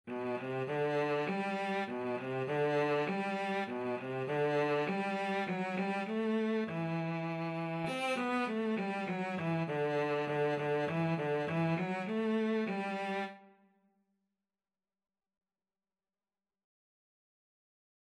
Free Sheet music for Cello
3/4 (View more 3/4 Music)
Moderato
G major (Sounding Pitch) (View more G major Music for Cello )
Cello  (View more Beginners Cello Music)
Traditional (View more Traditional Cello Music)
world (View more world Cello Music)